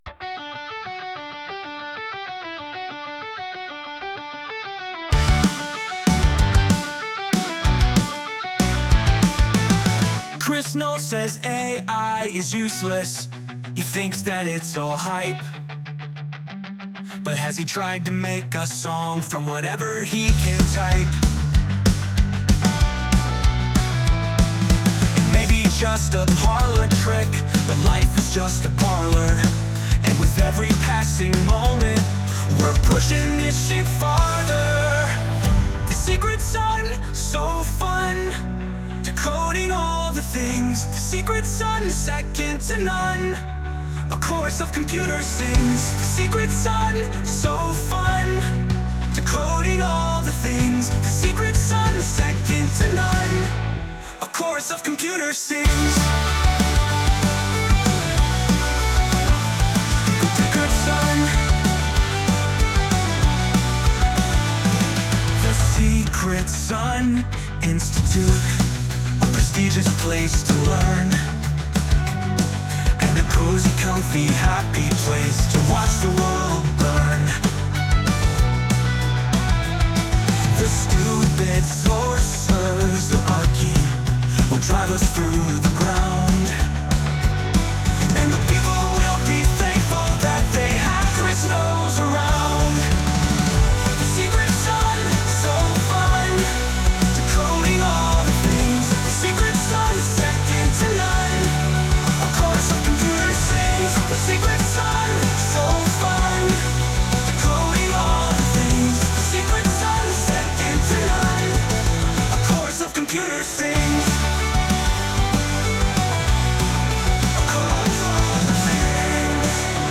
Get the full 2 hour interviews with THC+: Subscribe via our website and get the Plus show on your usual podcast apps.